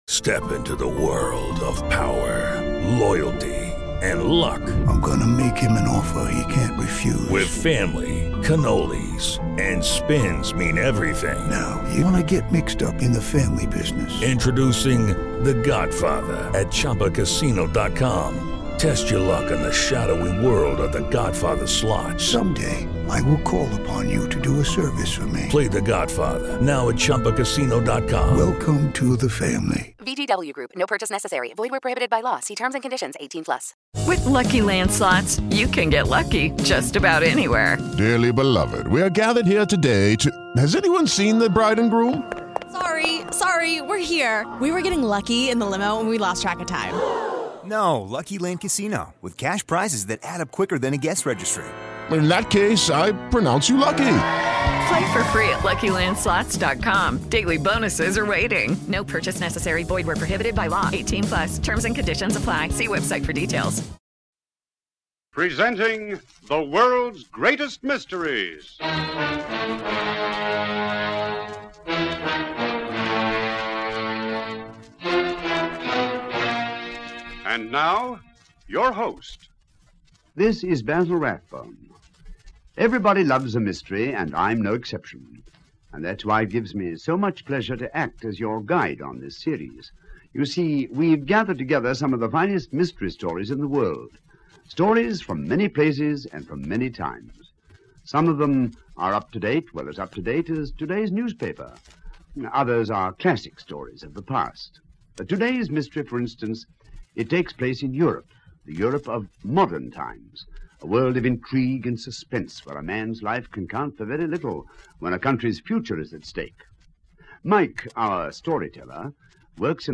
Europe Confidential, a classic from the golden age of radio!